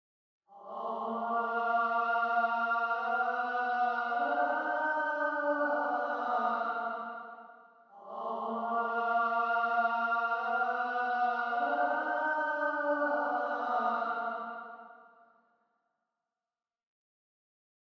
Звук в мечети зовет на молитву